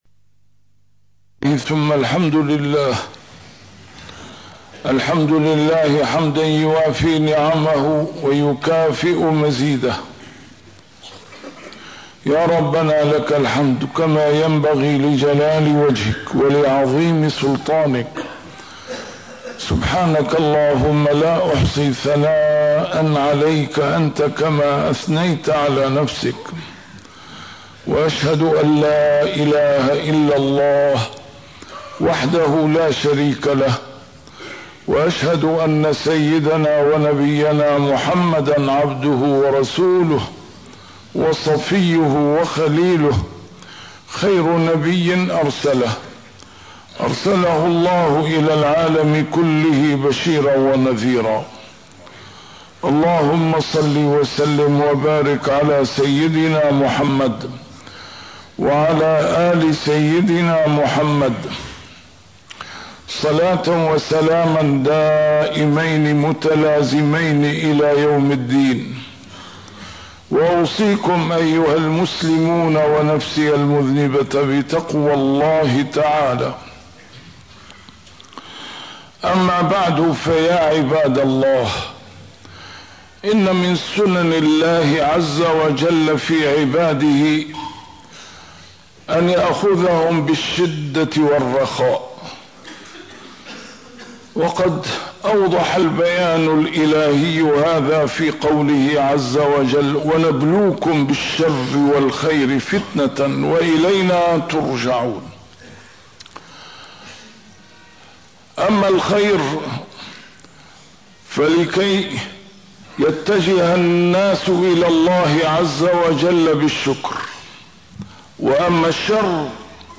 A MARTYR SCHOLAR: IMAM MUHAMMAD SAEED RAMADAN AL-BOUTI - الخطب - فَلَوْلا إِذْ جاءَهُمْ بَأْسُنا تَضَرَّعُوا